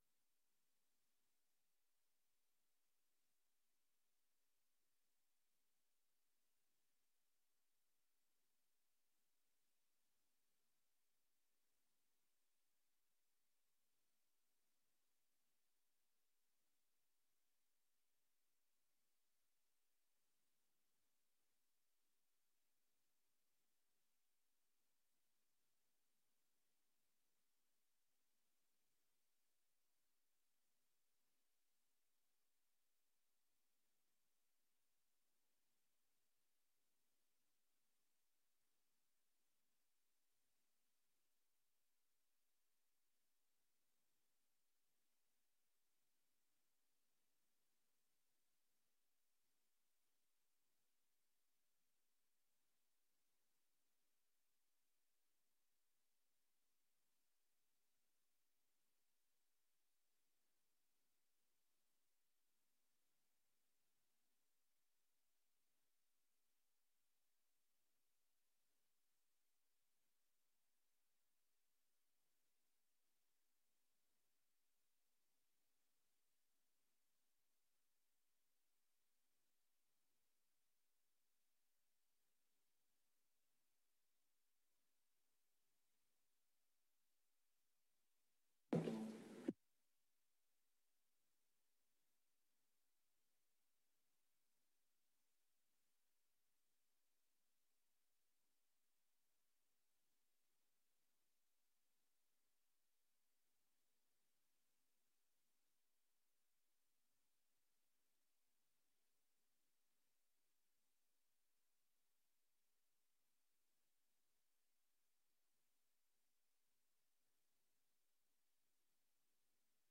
Raadsvergadering 27 november 2025 19:30:00, Gemeente Dronten
Download de volledige audio van deze vergadering